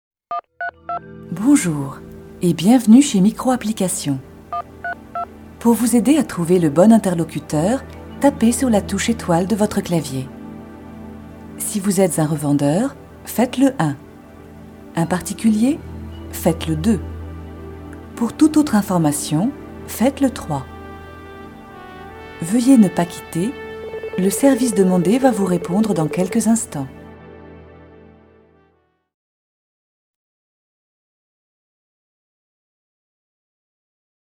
VOIX-OFF AUDIOGUIDES, E-LEARNING, DOCUMENTAIRES, INSTUTIONNELS
Sprechprobe: Industrie (Muttersprache):